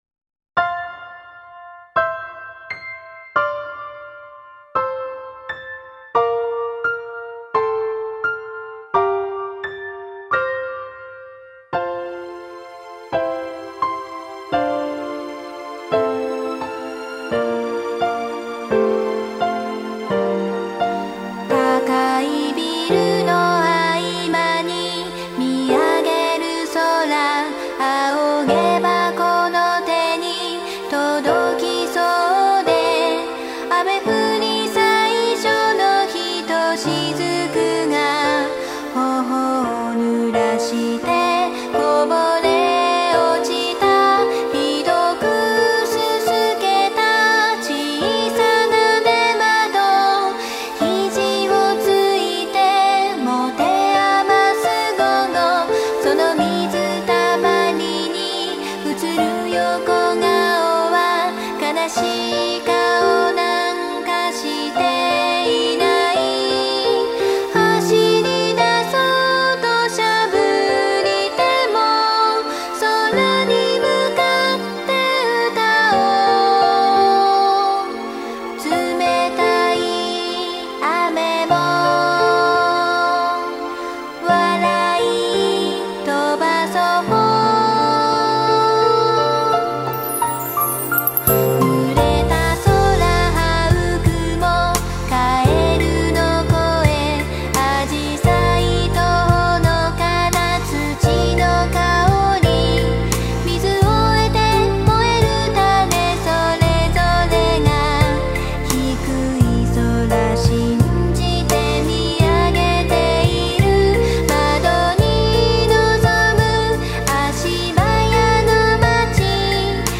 ・使用音源：YAMAHA motif-RACK